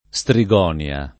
Strigonia [it. e lat.